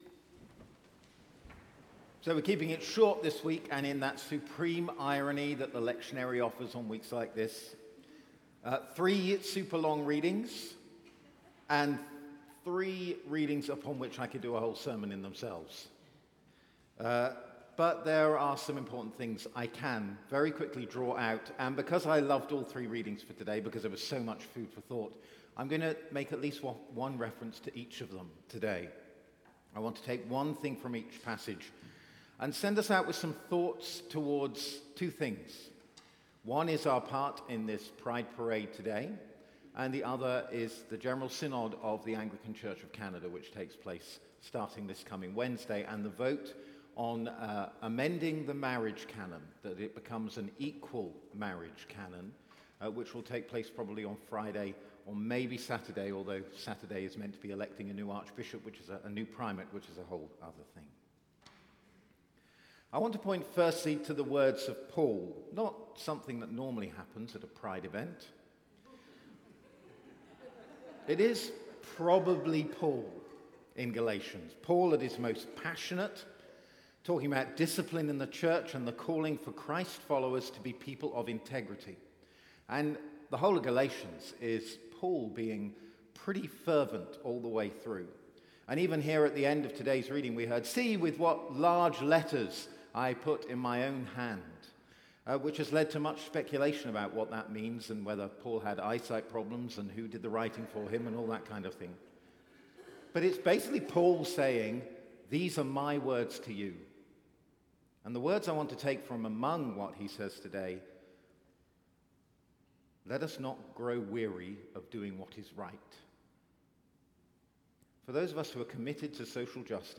Evensong Reflection